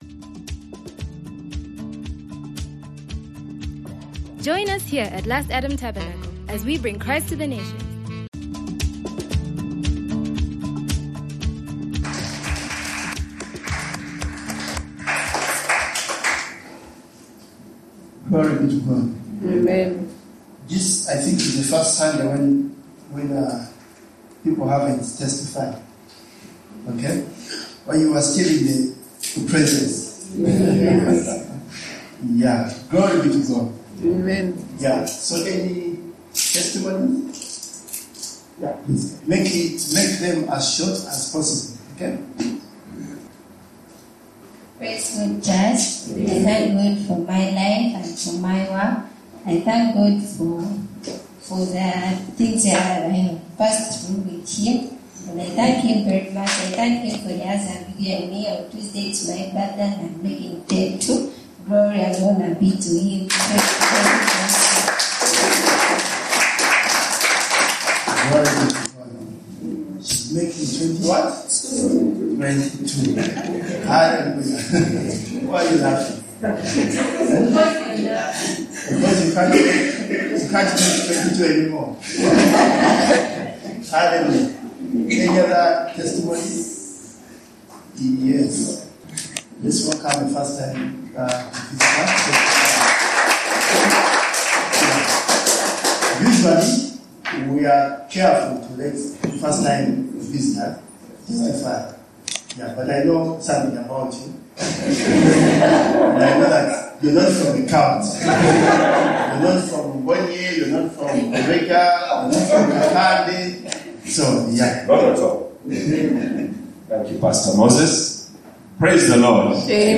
Note: Loss in quality of audio recording due to hollow and ambient sounds.